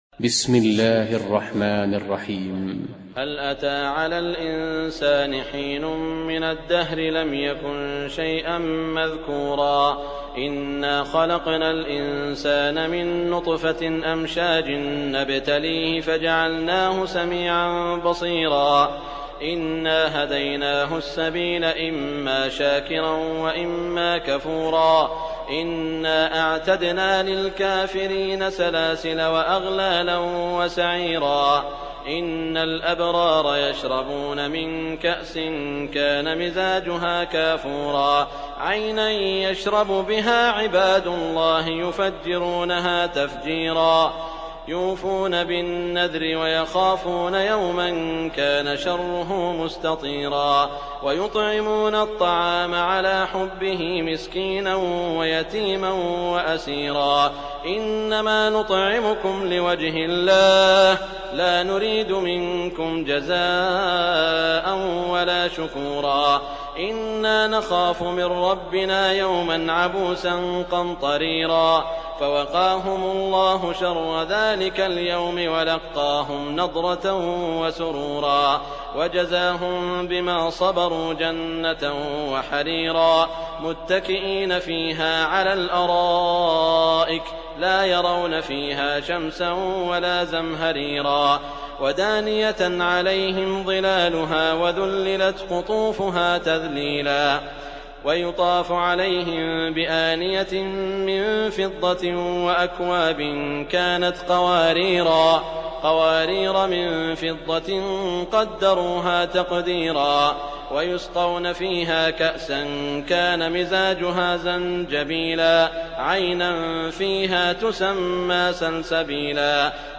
سورة الإنسان بصوت ثلاثة قراء